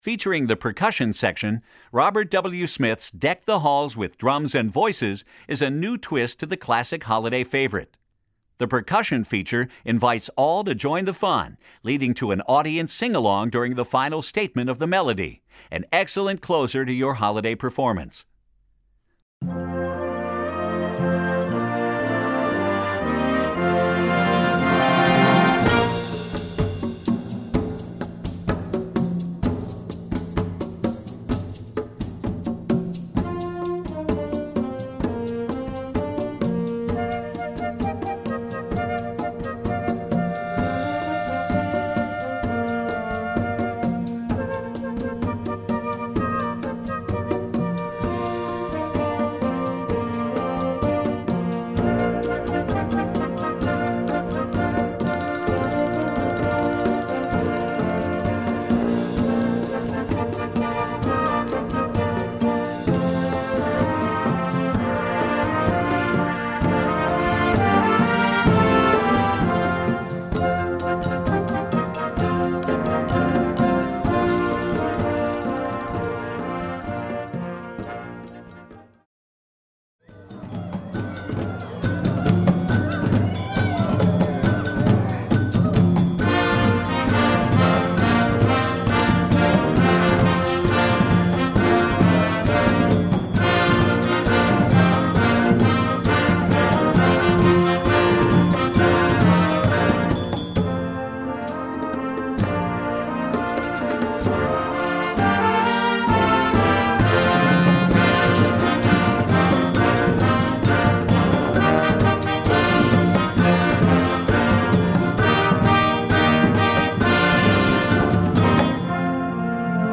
Besetzung: Blasorchester
Ein hervorragender Schluss für Ihr Weihnachtskonzert!